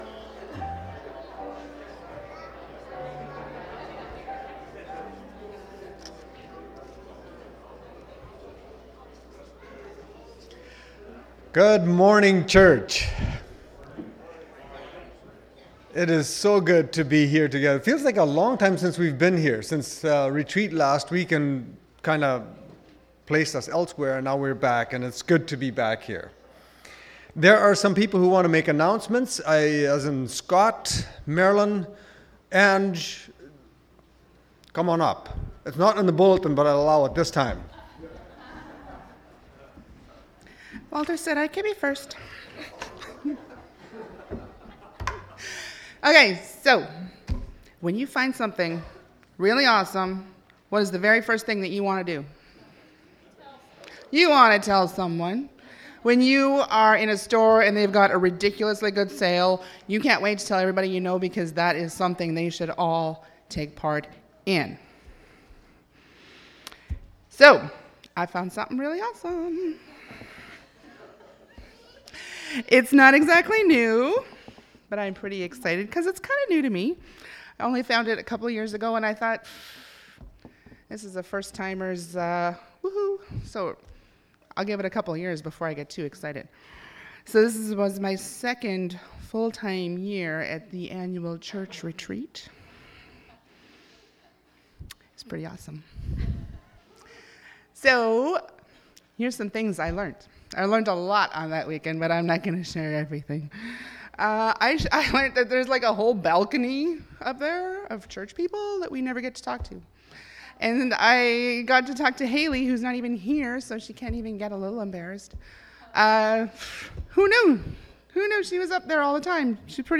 Audio Service from February 17